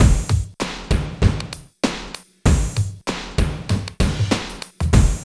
45 DRUM LP-R.wav